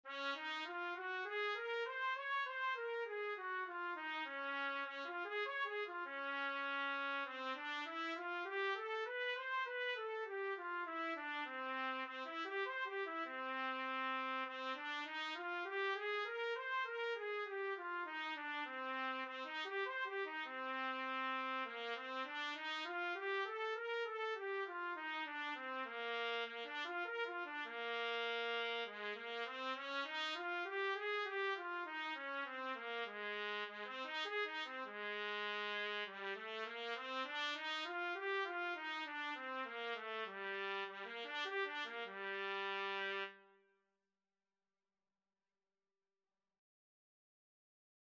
Trumpet scales and arpeggios - Grade 2
4/4 (View more 4/4 Music)
Db major (Sounding Pitch) Eb major (Trumpet in Bb) (View more Db major Music for Trumpet )
G4-Db6
trumpet_scales_grade2.mp3